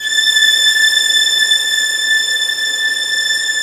Index of /90_sSampleCDs/AKAI S-Series CD-ROM Sound Library VOL-7/ORCH STRINGS